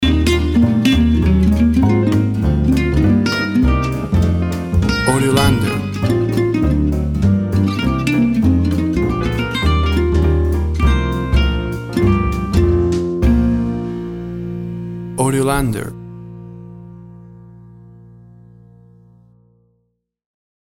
Smooth jazz piano mixed with jazz bass and cool jazz drums.
Tempo (BPM) 200